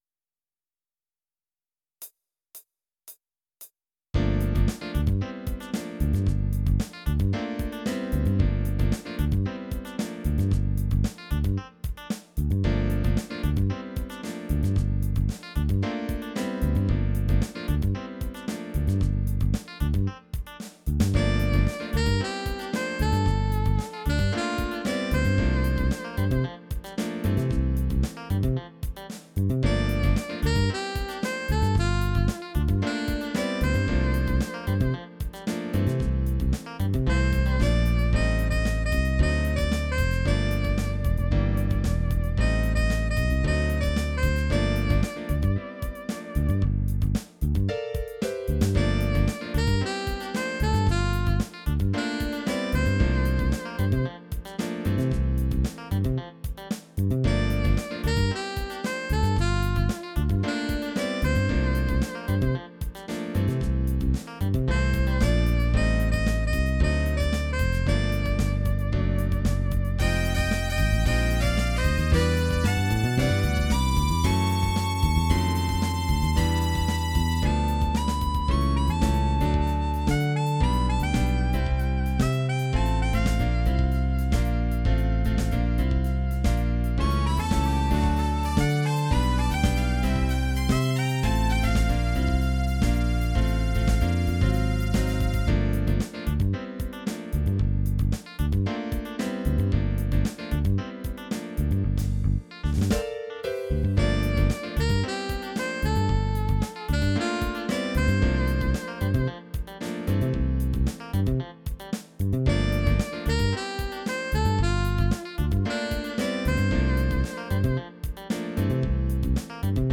version instrumentale multipistes